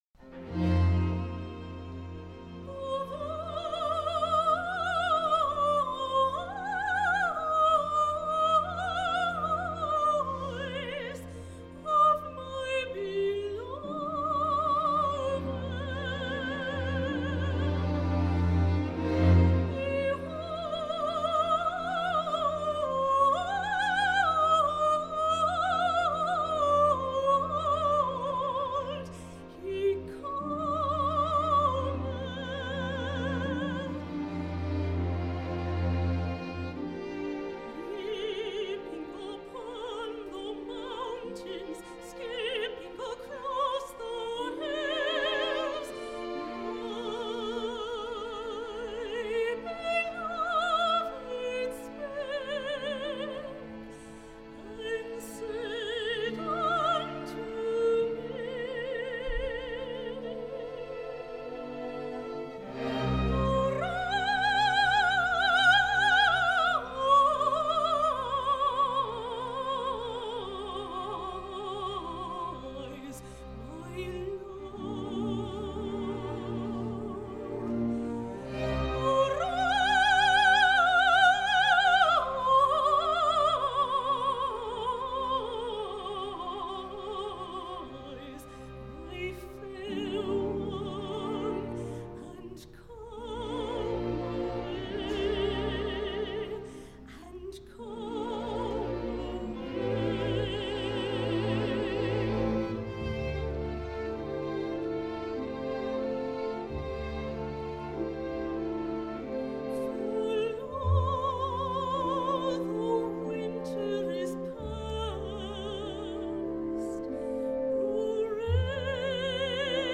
A rapturous setting
Category: Voices with Orchestra